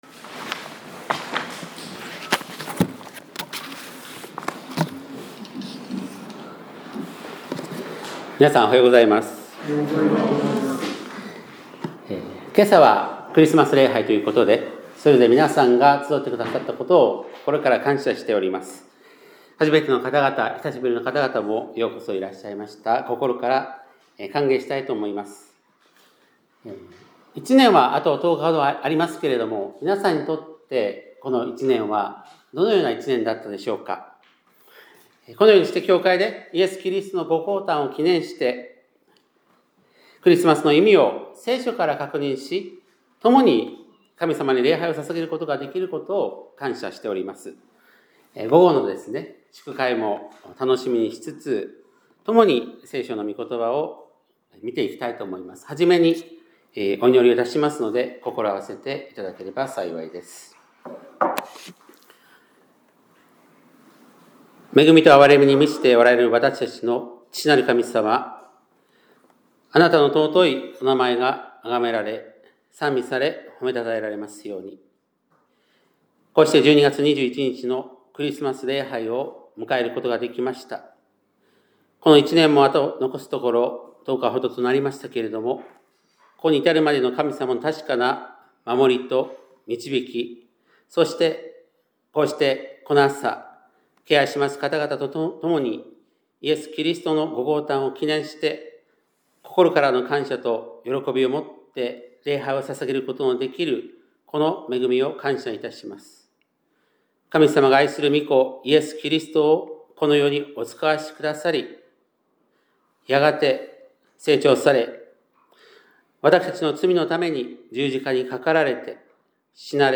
2025年12月21日（日）礼拝メッセージ